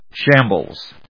音節sham・bles 発音記号・読み方
/ʃˈæmblz(米国英語)/